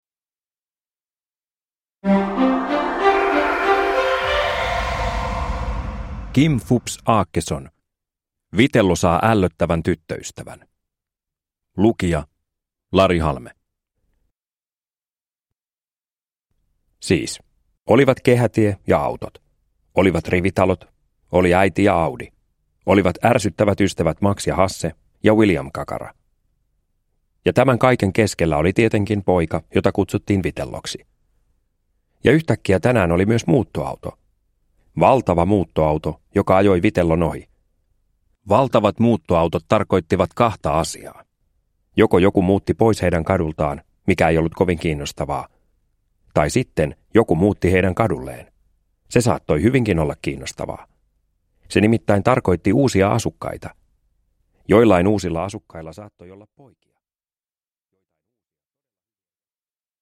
Vitello saa ällöttävän tyttöystävän (ljudbok) av Kim Fupz Aakeson